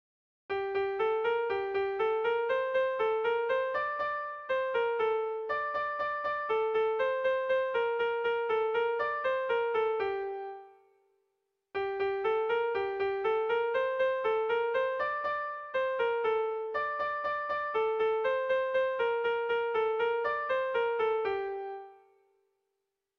Air de bertsos - Voir fiche   Pour savoir plus sur cette section
Erromantzea
Lauko handia (hg) / Bi puntuko handia (ip)
AB